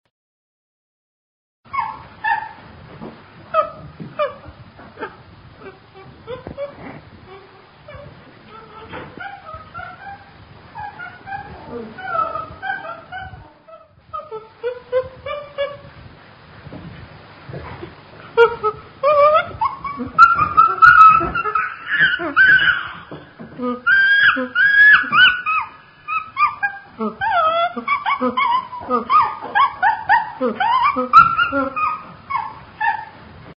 Chimp Klingelton | Kostenlos Herunterladen
0:00 Group: Tiere ( 661 226 ) Rate this post Download Here!